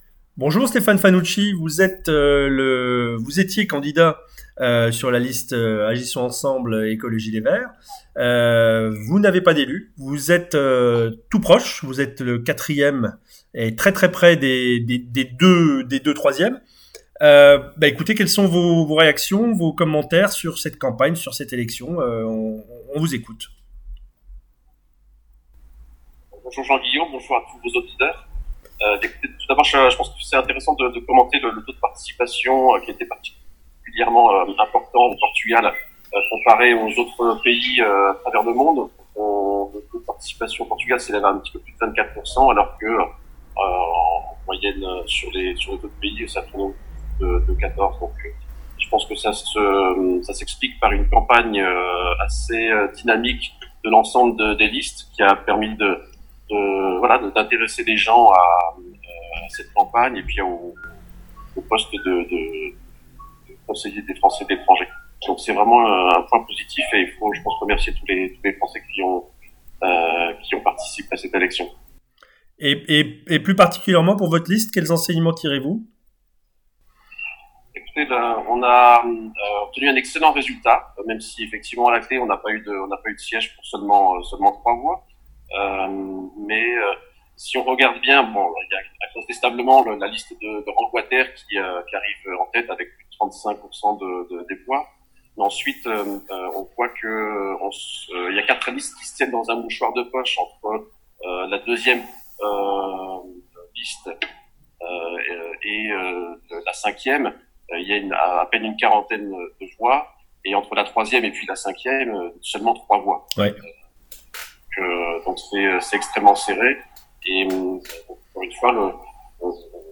3. Interview du jour